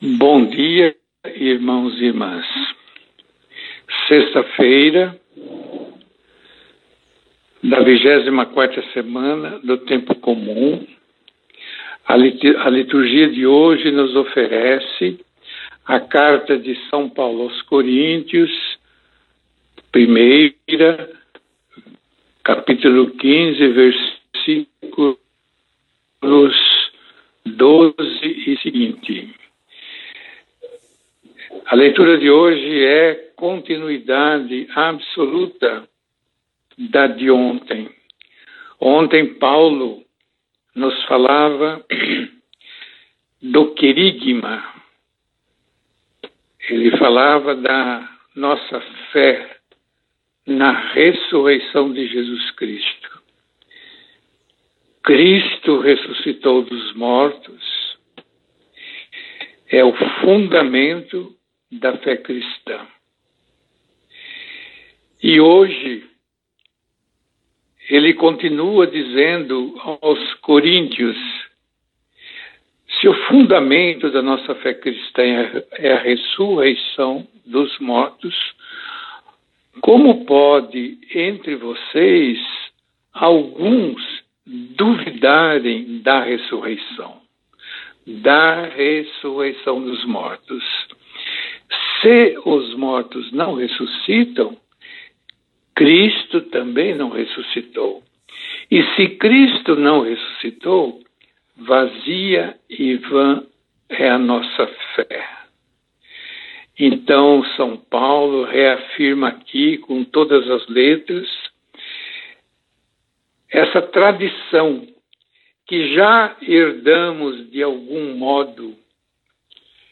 No áudio com música desta quinta-feira